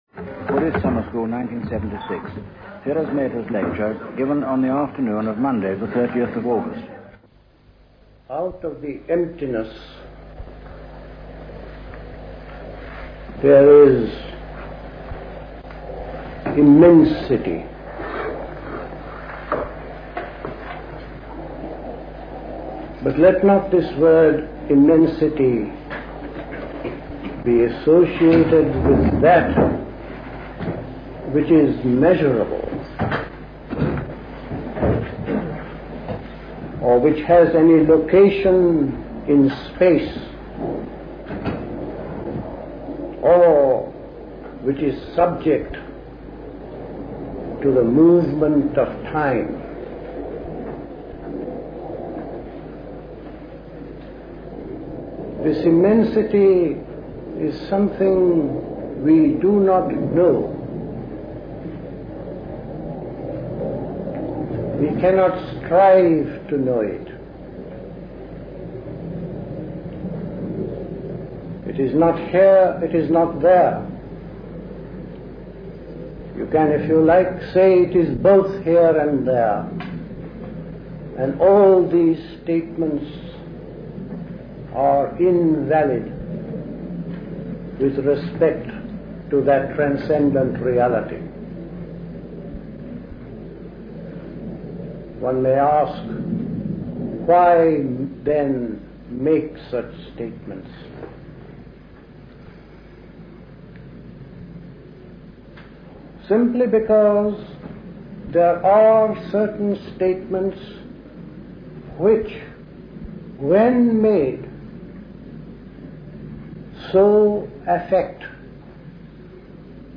A talk given
at High Leigh Conference Centre, Hoddesdon, Hertfordshire